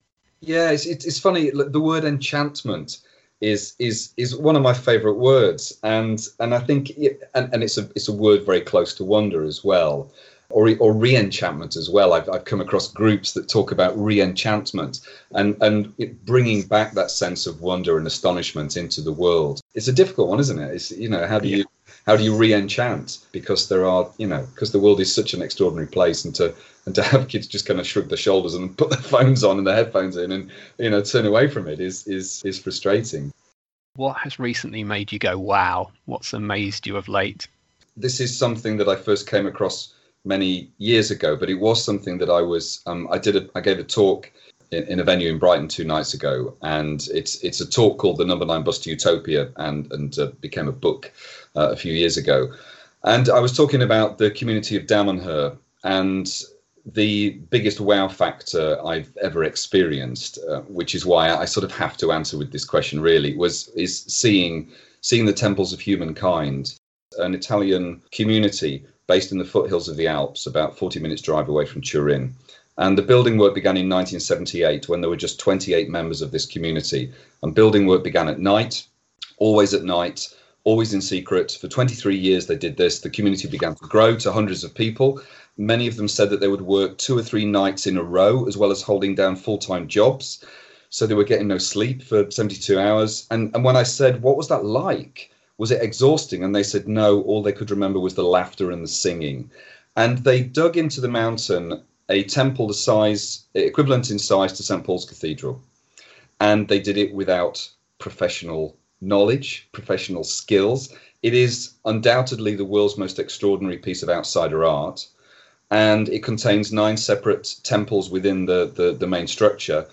This was such a brilliant interview that it seemed a shame to transcribe it and reduce it down to mere text. Which means this will be the first audio “ Words on Wonder ” for the project.